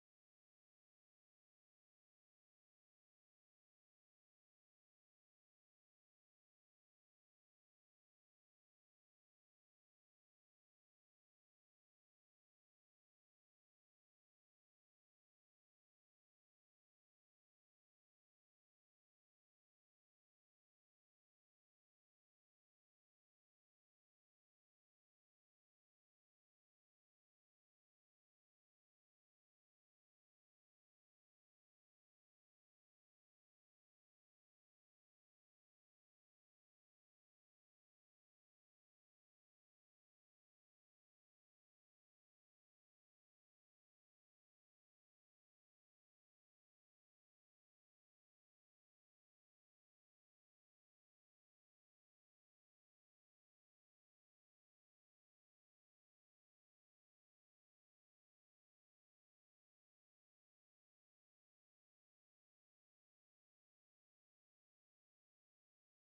Marzo 4 FOH. Box Culiacán sound effects free download